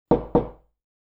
دانلود صدای در چوبی 3 از ساعد نیوز با لینک مستقیم و کیفیت بالا
جلوه های صوتی
برچسب: دانلود آهنگ های افکت صوتی اشیاء دانلود آلبوم صدای کوبیدن در چوبی از افکت صوتی اشیاء